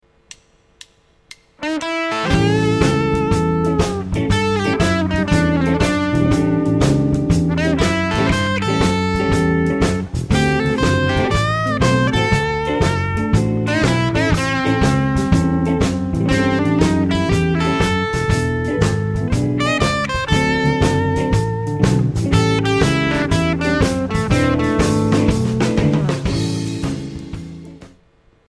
Licks as Bricks – Building a Blues Guitar Solo
To make the notes more expressive techniques including slides, hammer-ons and pull-offs are used.
The final solo will alternate between the bending lick variations and the non bending phrases to create a cohesive and flowing solo.
blueslicks_solo1.mp3